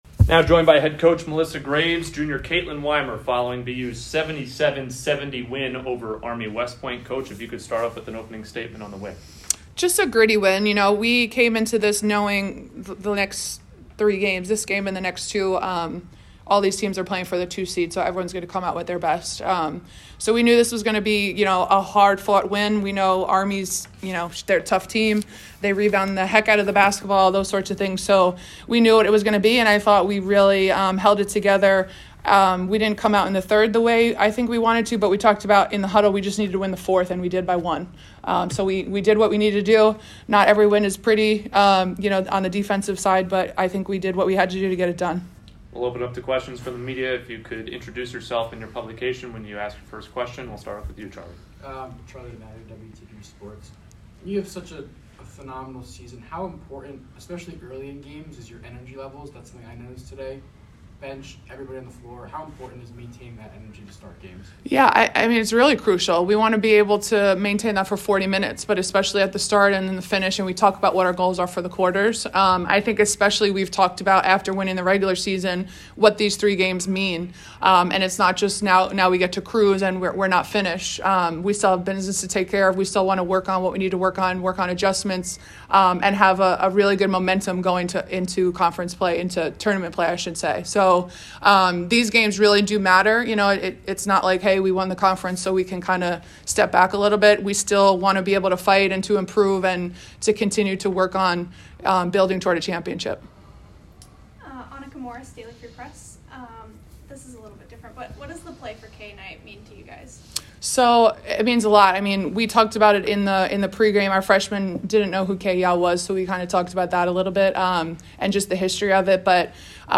Army Postgame Interview